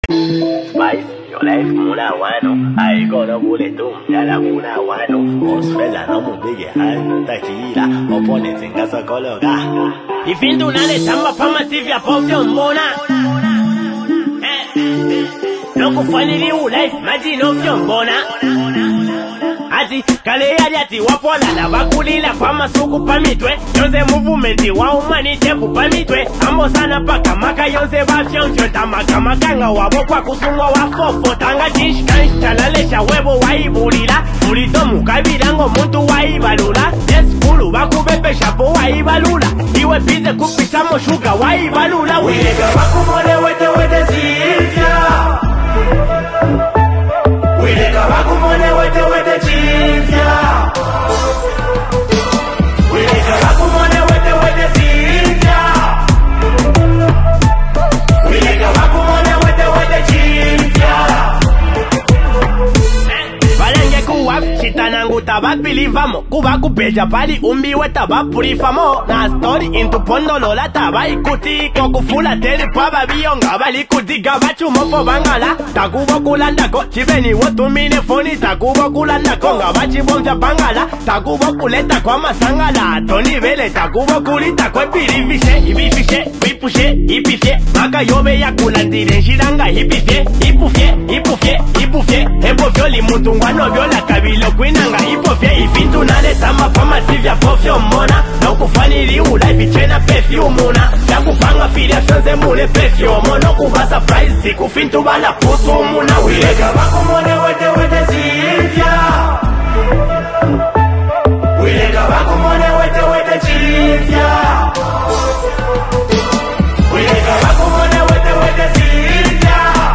Copperbelt Rapper
a feel-good vibe